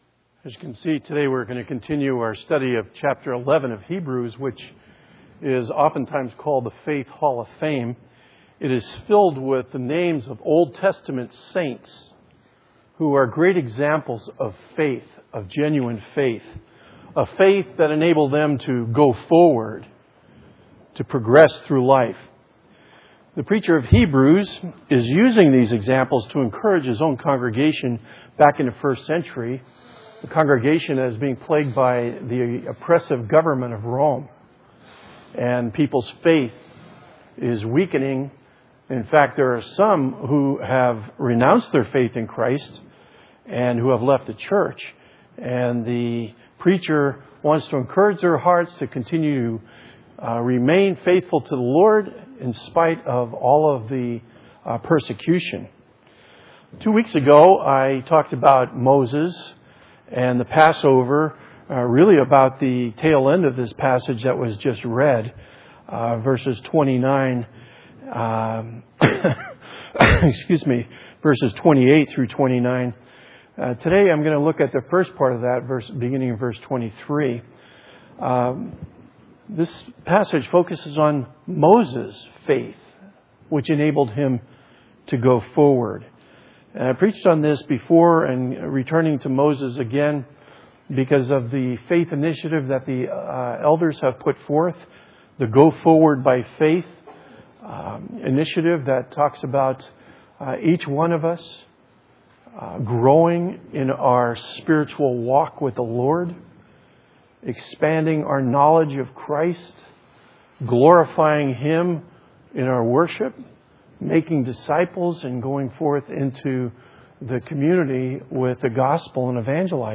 A Collection of 2016 Sermons from Windsor Baptist Chruch